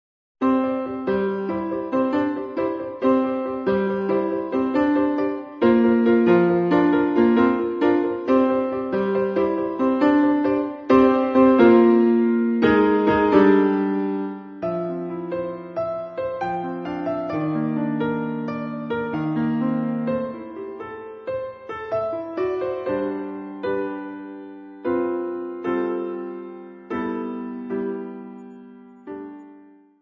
Besetzung: Klavier